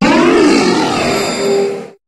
Cri de Necrozma Crinière du Couchant dans Pokémon HOME.
Cri_0800_Crinière_du_Couchant_HOME.ogg